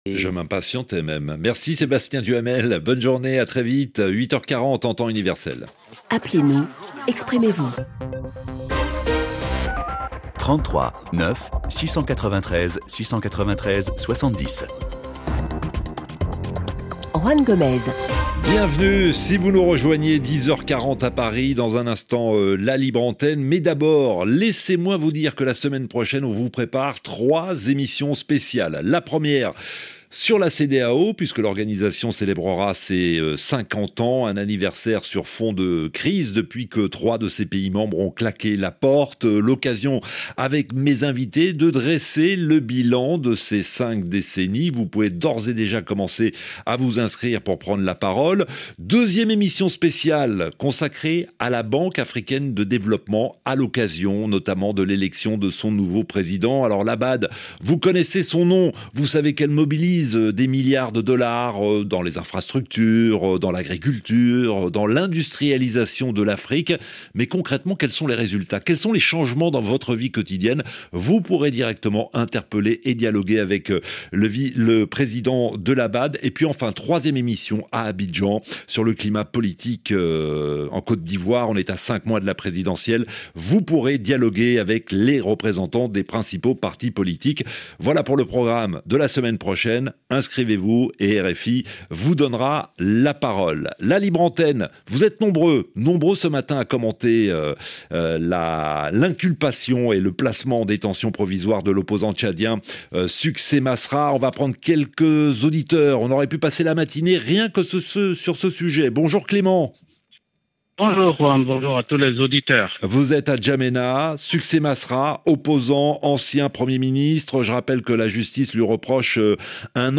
Le rendez-vous interactif des auditeurs de RFI.